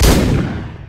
gun3.ogg